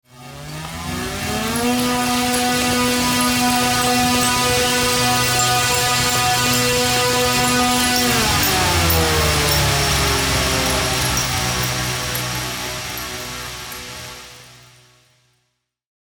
Astral Siren Sound Button - Free Download & Play